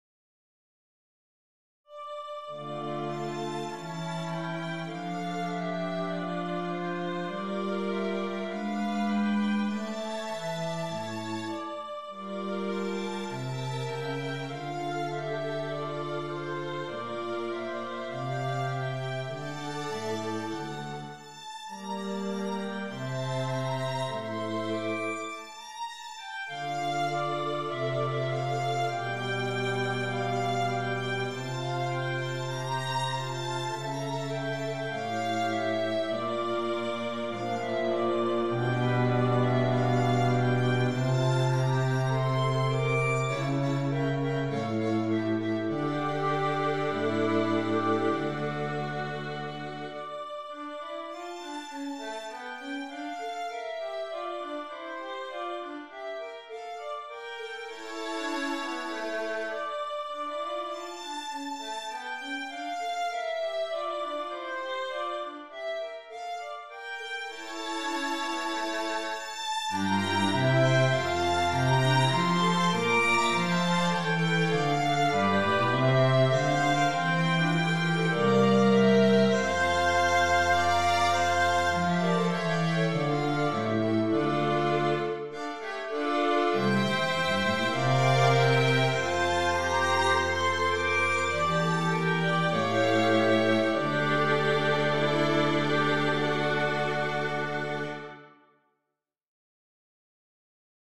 Contemporary and Classical Arr.
(D minor)